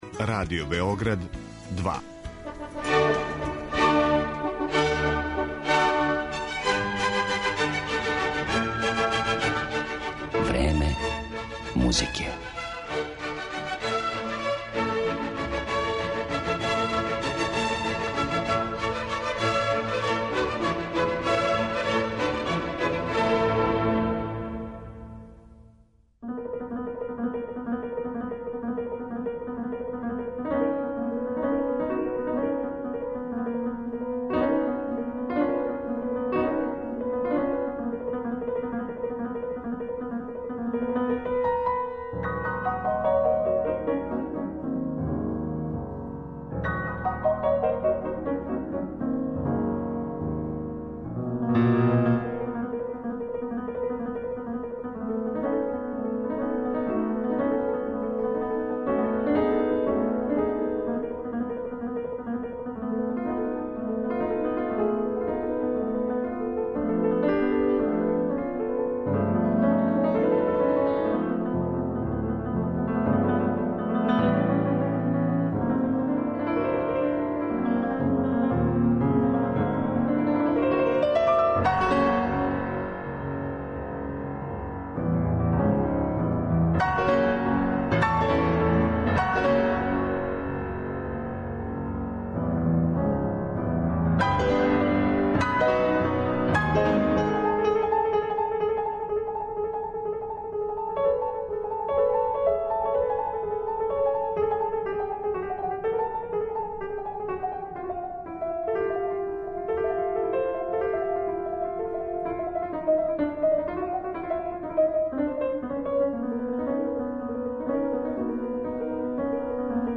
Уз одломке интервјуа снимљених приликом ранијих гостовања у Београду, овог познатог француског уметника, чију потпуну посвећеност квалитету звука, као и деликатност и рафинираност музичког израза, критичари непрестано истичу, представићемо у данашњој емисији делима Дебисија, Листа, Шуберта, Менделсона и других.